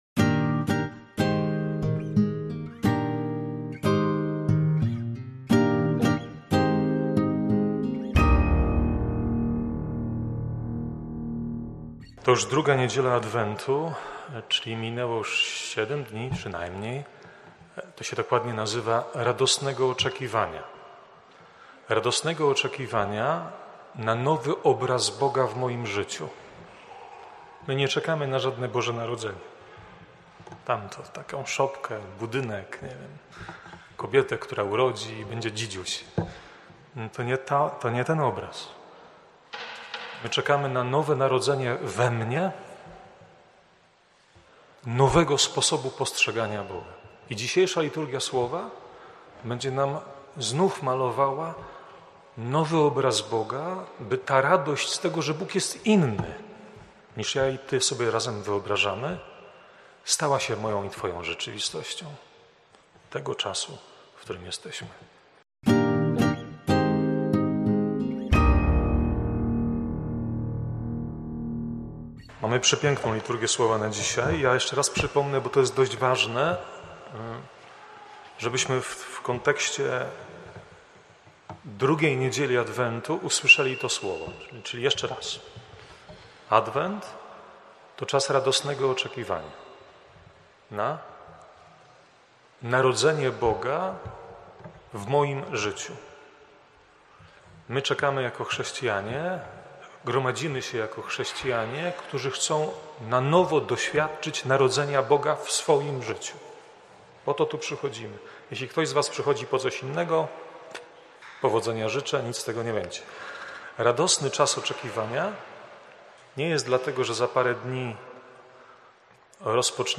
kazania.
wprowadzenie do Liturgii, oraz homilia: